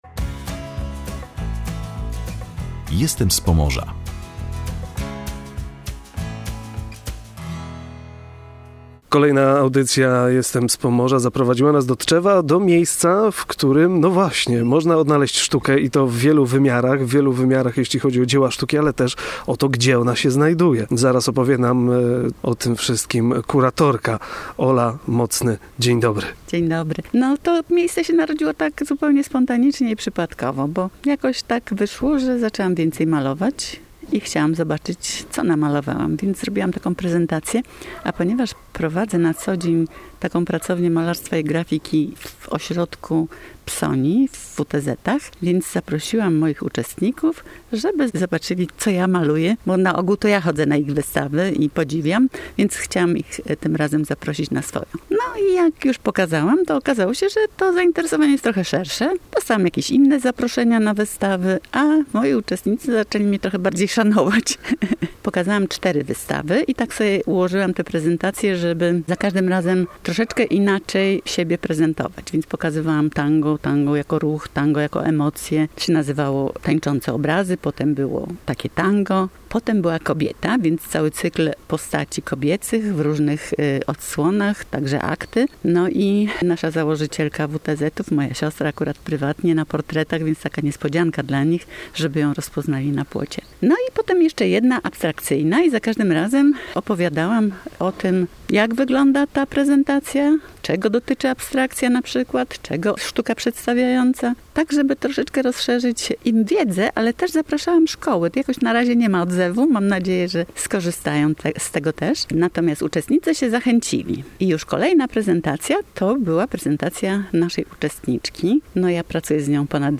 W audycji "Jestem z Pomorza" odwiedziliśmy Tczew, gdzie na ogrodowym płocie przy ulicy Czyżykowskiej powstało niezwykłe miejsce: Galeria na Płocie.
W rozmowie